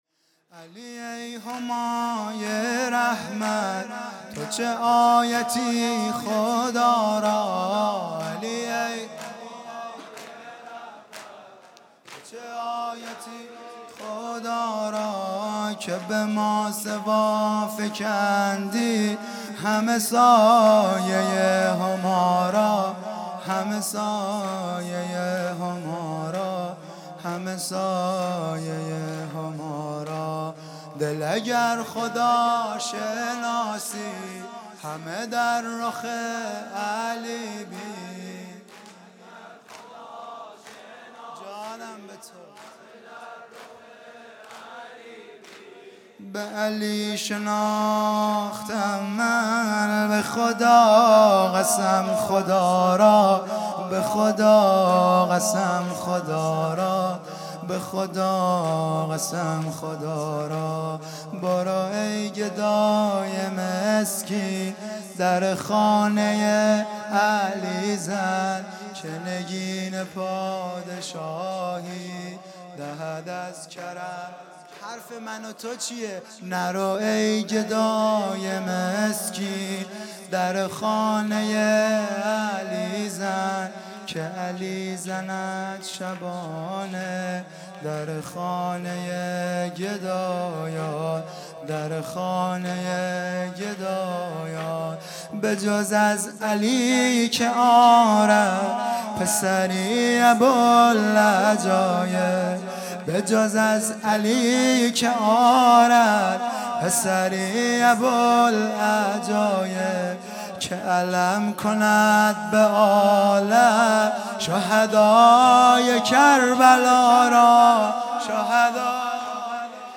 هیئت دانشجویی فاطمیون دانشگاه یزد